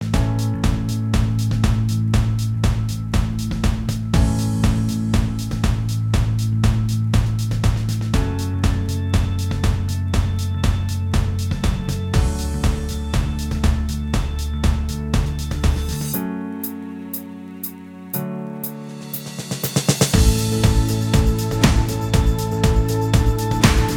Minus Main Guitar Pop